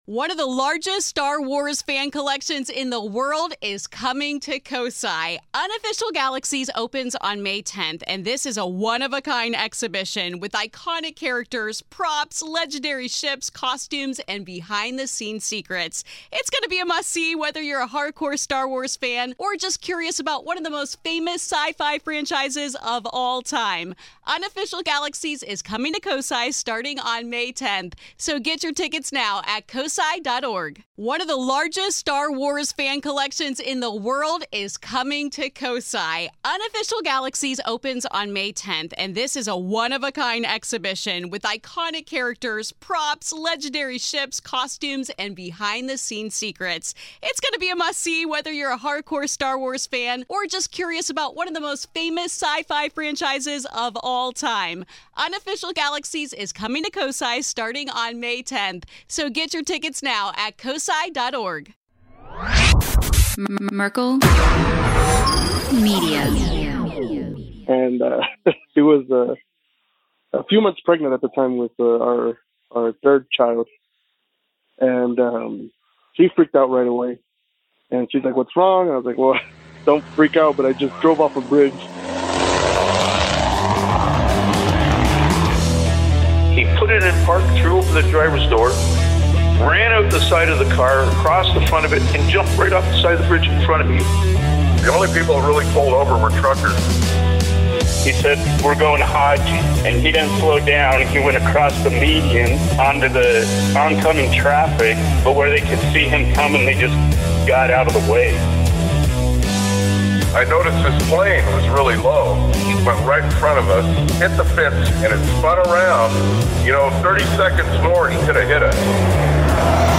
78: I Drove Off A Bridge | Call-In Show